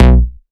VES2 Bass Shots
VES2 Bass Shot 091 - B.wav